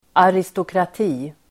Ladda ner uttalet
Uttal: [aristokrat'i:]